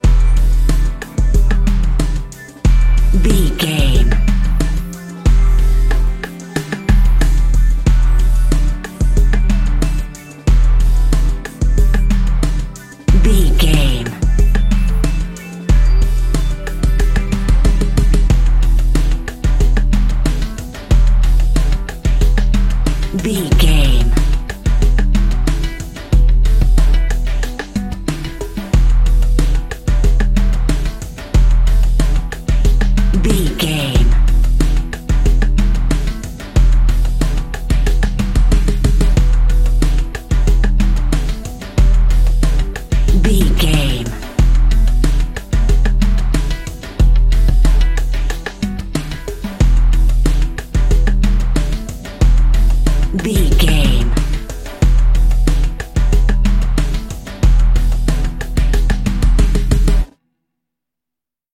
Aeolian/Minor
synthesiser
drum machine
hip hop
soul
Funk
acid jazz
energetic
cheerful
bouncy
funky